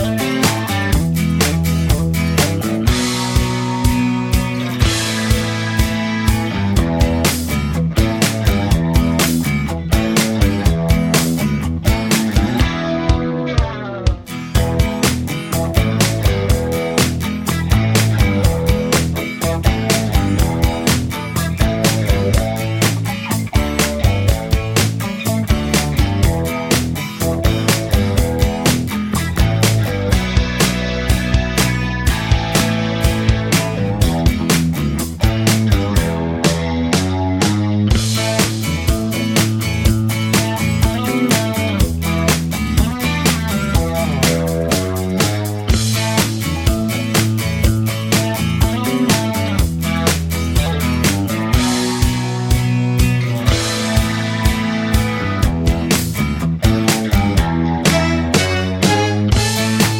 Spoken Part Cut Christmas 2:50 Buy £1.50